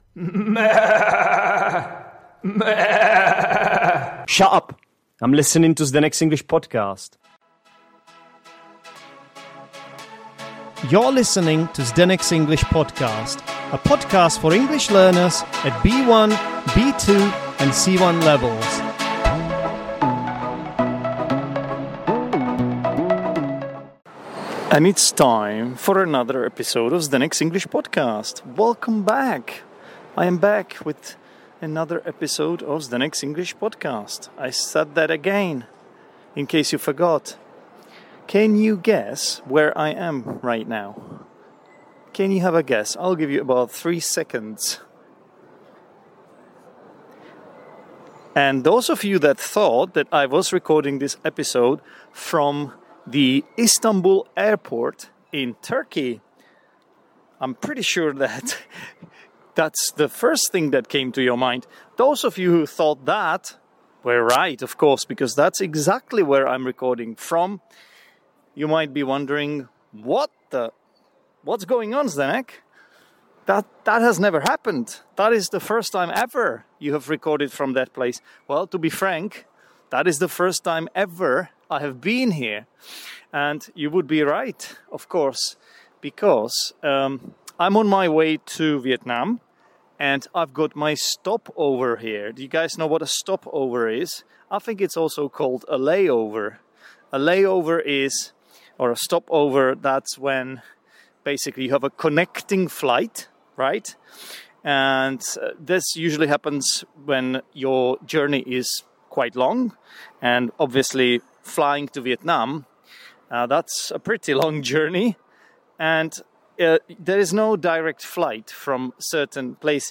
In this episode, recorded at the airport, I share what happened, along with some of my upcoming plans in Vietnam.